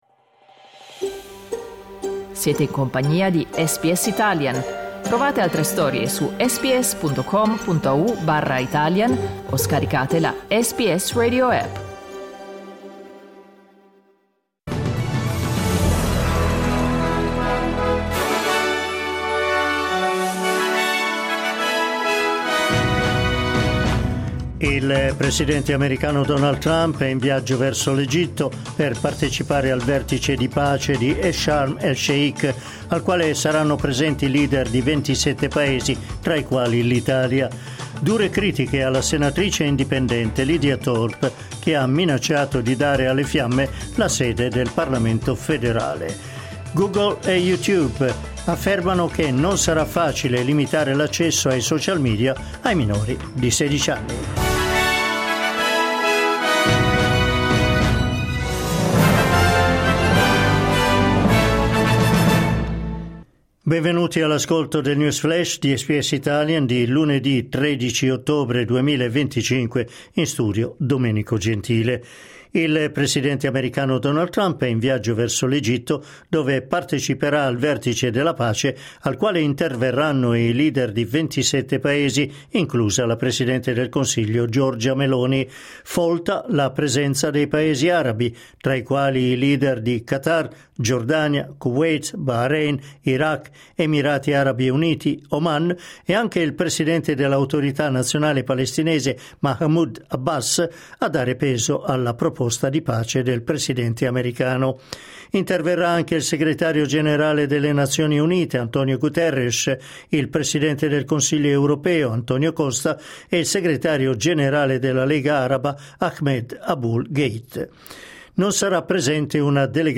News flash lunedì 13 ottobre 2025